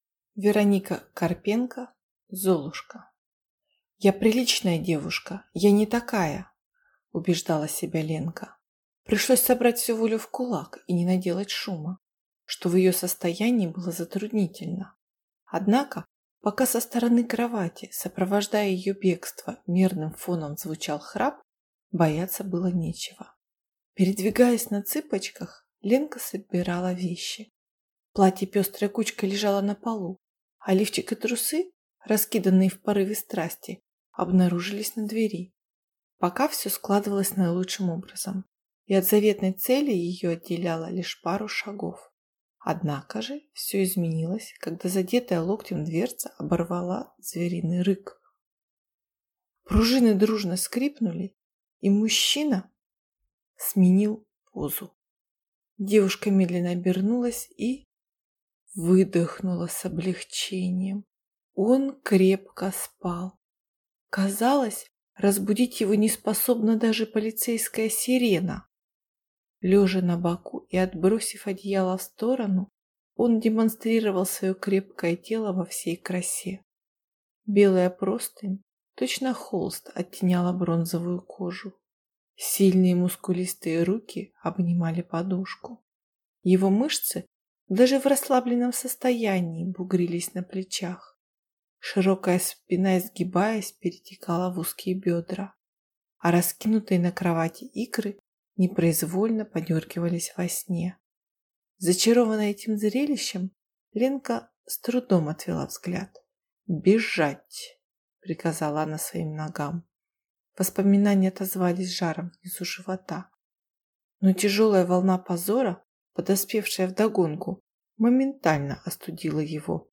Аудиокнига Золушка | Библиотека аудиокниг